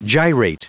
Prononciation du mot gyrate en anglais (fichier audio)
Prononciation du mot : gyrate